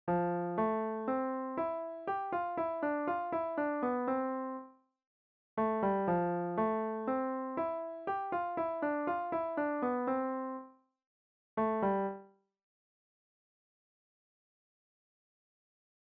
Here's a two measure lick ending on beat one of the next measure.